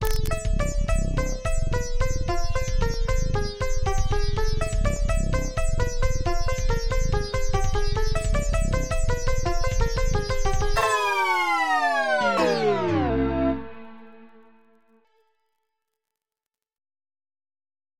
MIDI 6.06 KB MP3 (Converted) 0.28 MB MIDI-XML Sheet Music